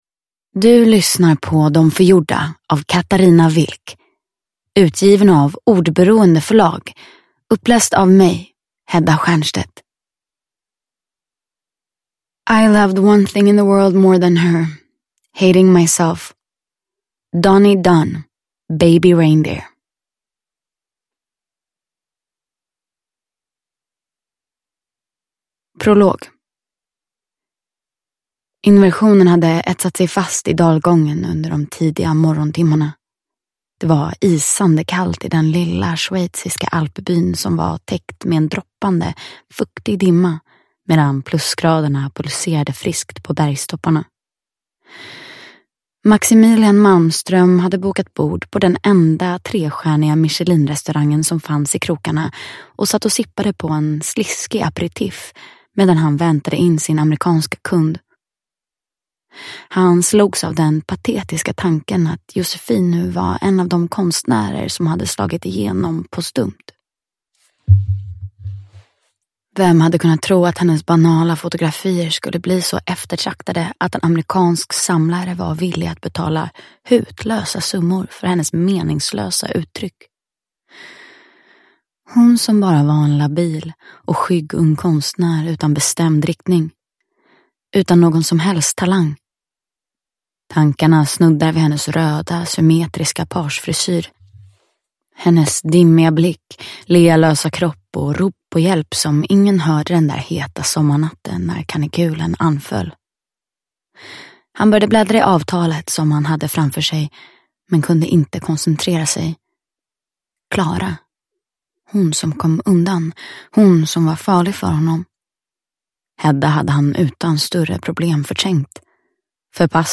Uppläsare: Hedda Stiernstedt
Ljudbok